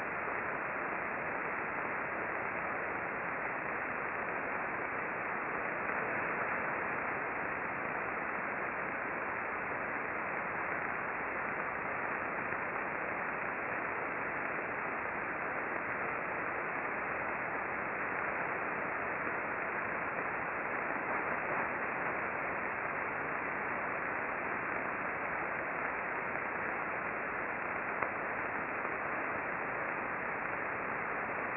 RFI with a popping sound is apparent in the recordings.
Toward the end of the storm, bursts were recorded on the higher channel but the lower channel also was active.